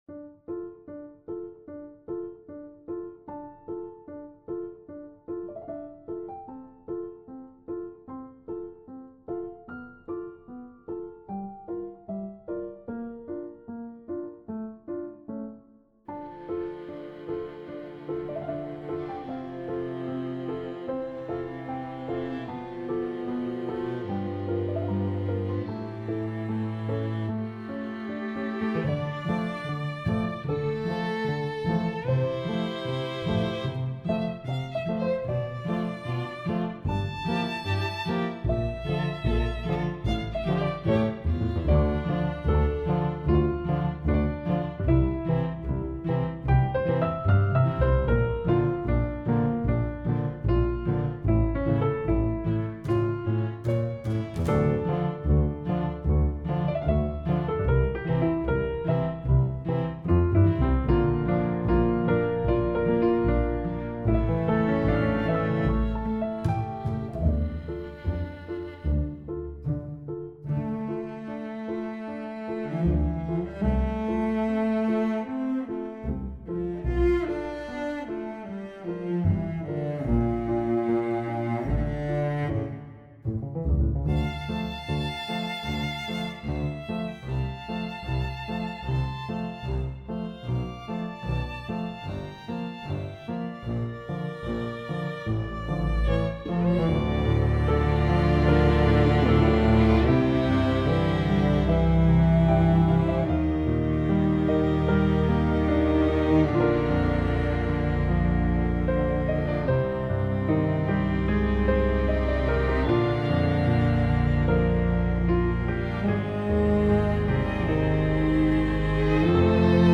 Written for String Quintet x Piano. Wrote it as a representation of how tense things are in the world nowadays.